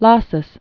(läsəs), Roland de or Orlande de 1532-1594.